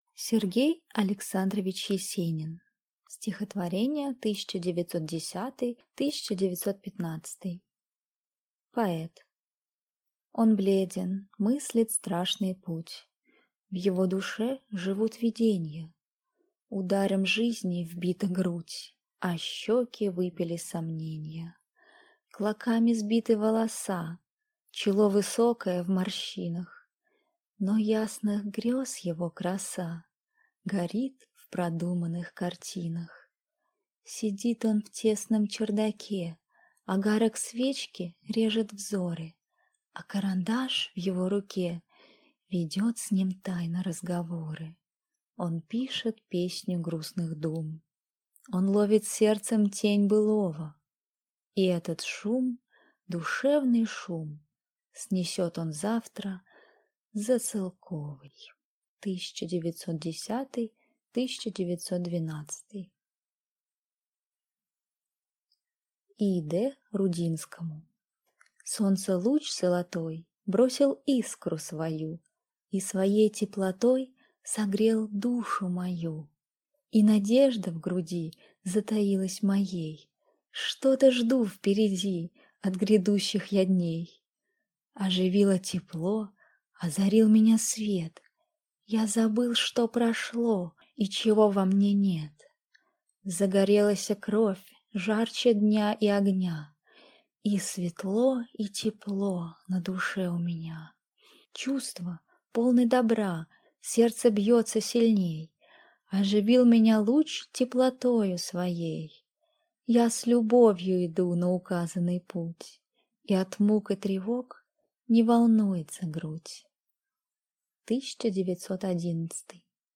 Аудиокнига Стихотворения 1910 – 1915 | Библиотека аудиокниг